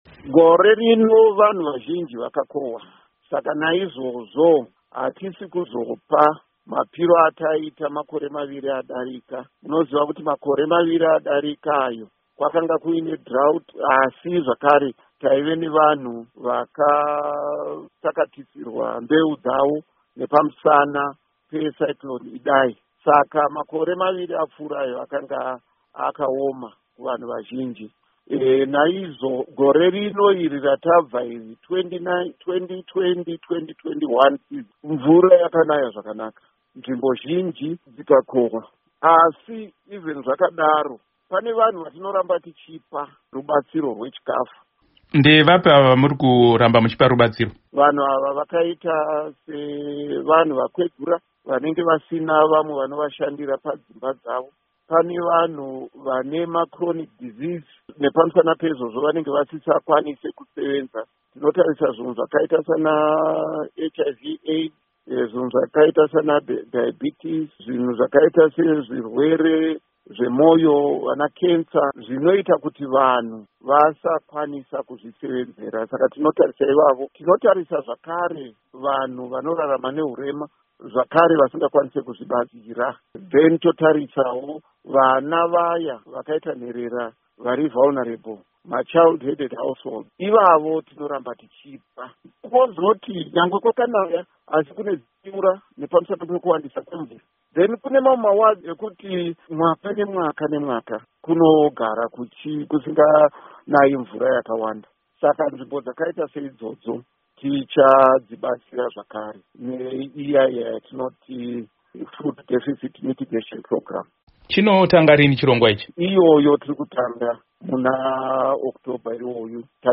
Hurukuro naMuzvinafundo Paul Mavima